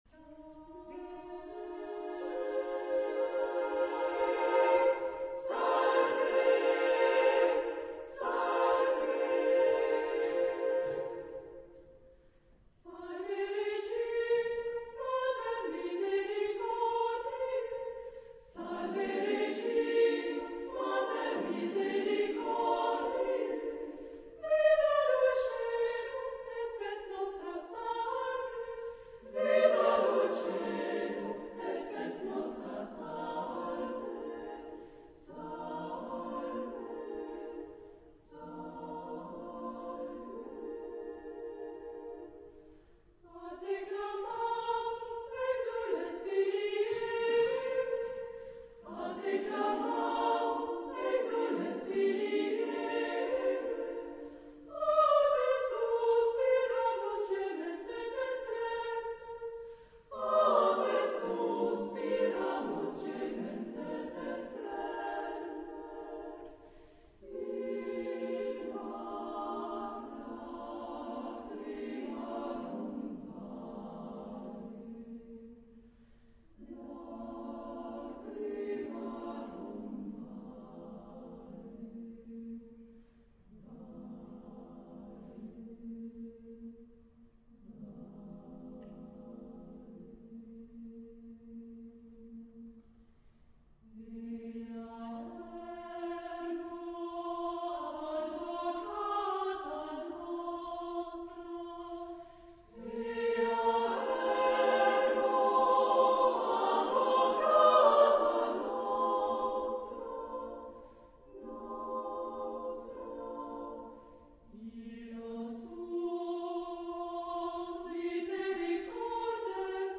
Epoque: 20th century
Genre-Style-Form: Sacred
Type of Choir: SSAA  (4 women voices )